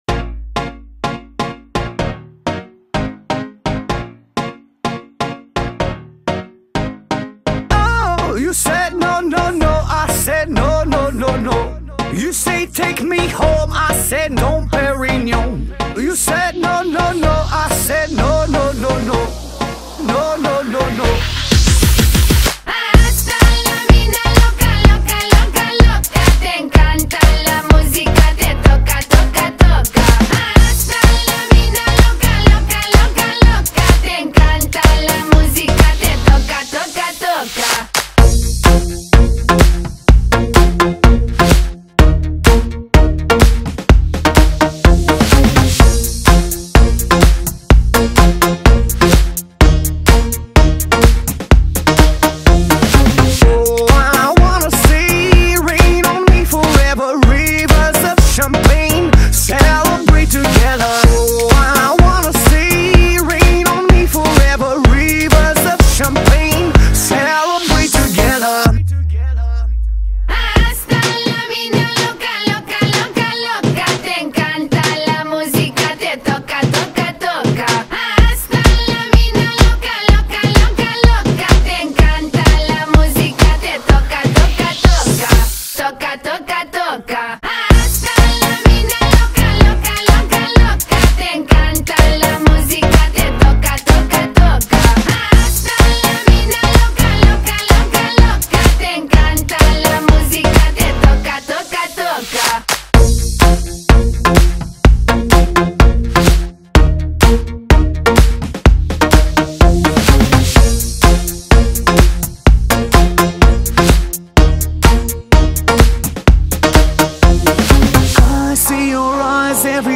فضای آهنگ کاملاً برای ایجاد حس سرزندگی و حرکت طراحی شده است.
شاد